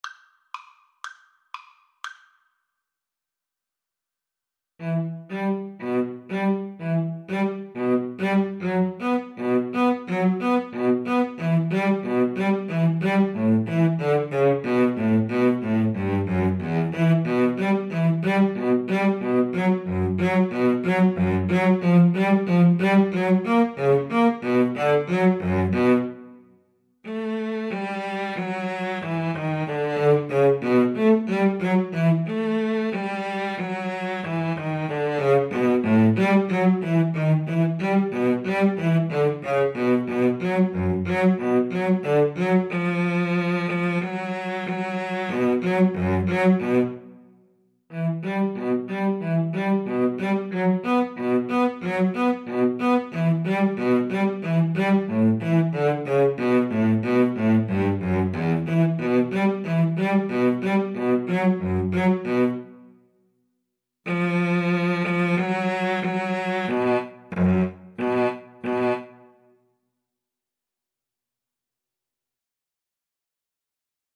Steadily (first time) =c.60